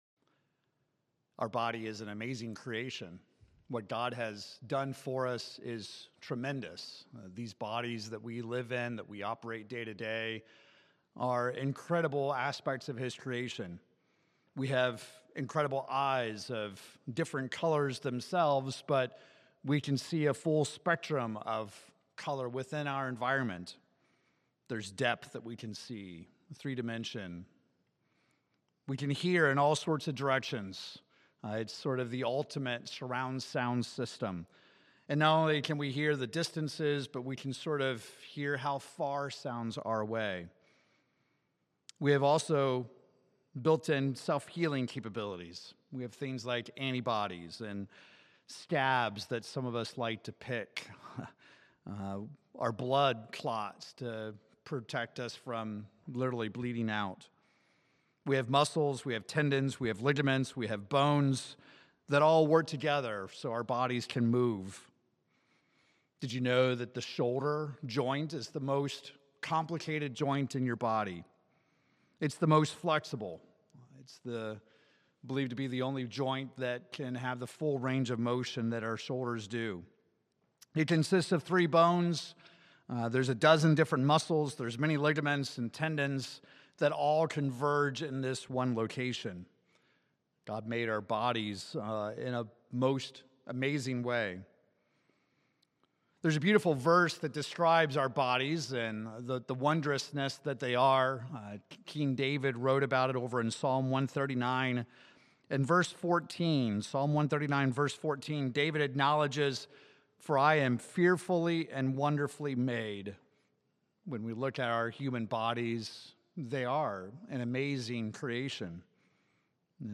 In this sermon we review how to monitor our own spiritual condition so we are not spiritually comatose.